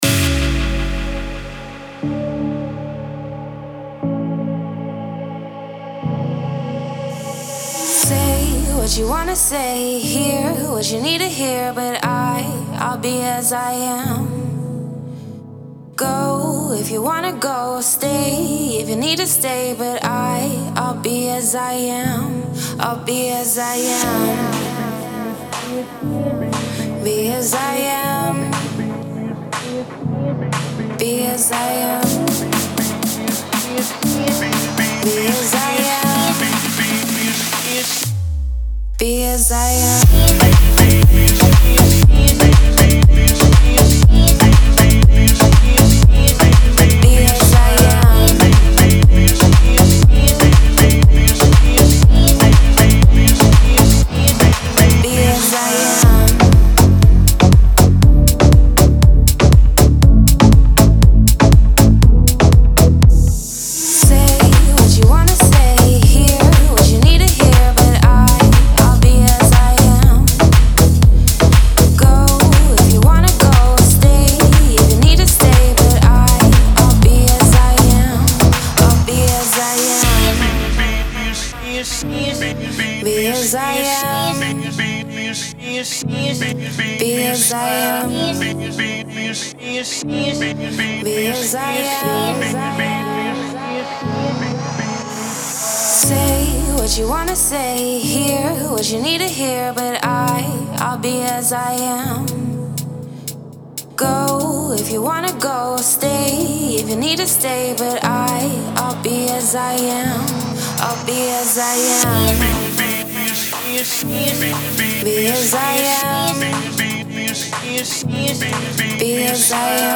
Genre : Deep House